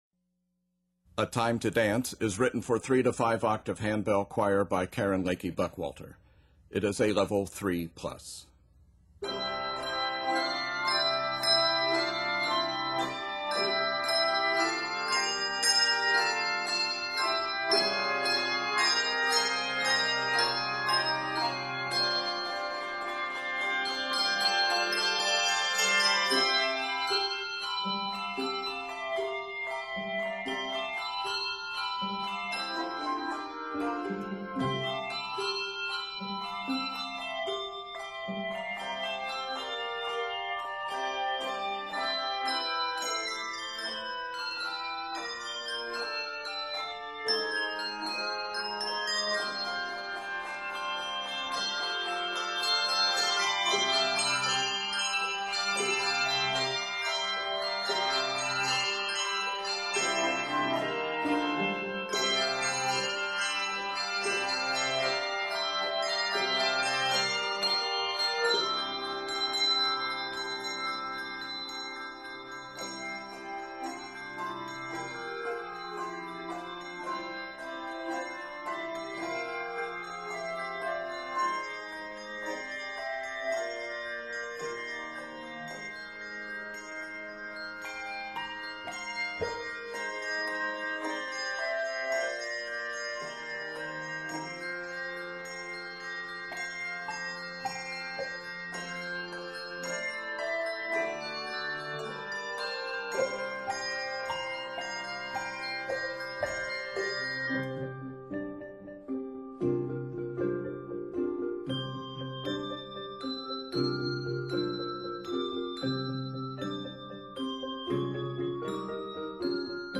is scored in C Major
N/A Octaves: 3-5 Level